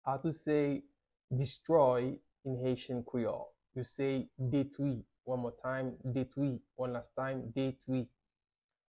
How to say Destroy in Haitian Creole - Detwi pronunciation by a private Haitian tutor
“Detwi” Pronunciation in Haitian Creole by a native Haitian can be heard in the audio here or in the video below: